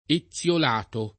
eZZLol#to] o etiolato [